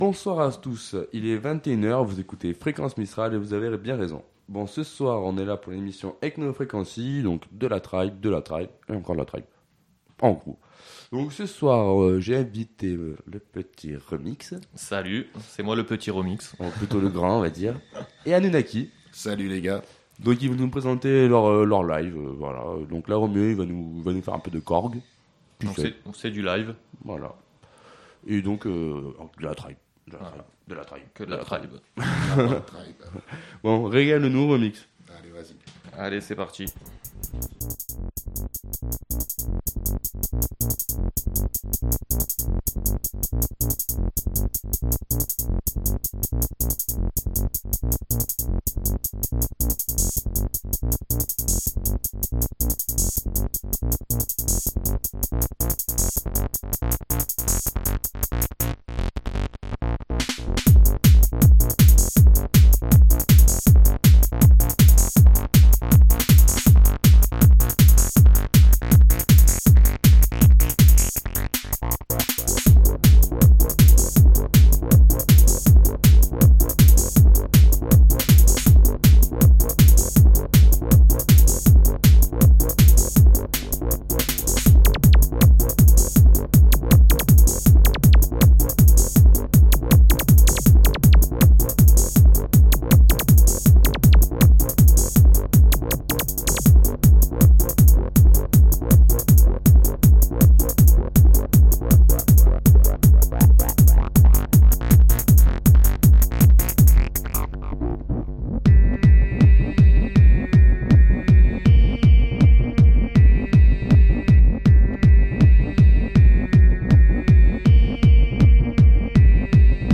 Emission 100% acid et tribe